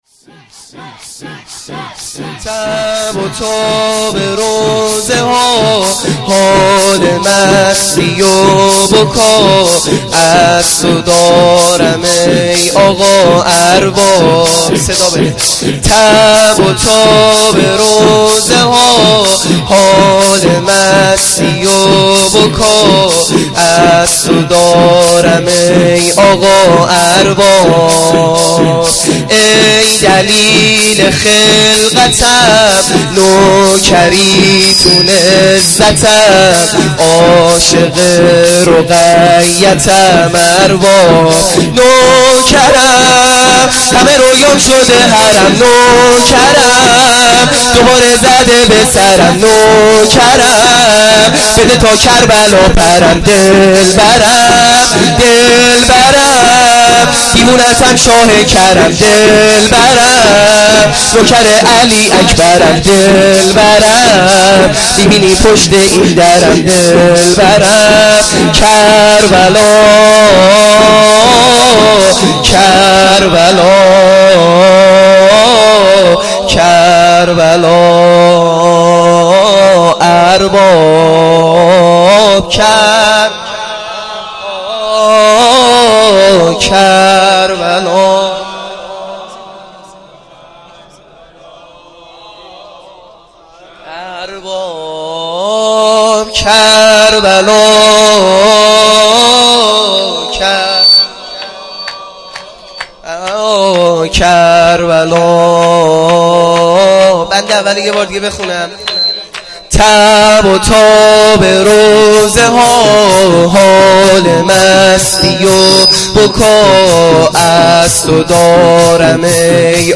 شور | تب و تاب روضه ها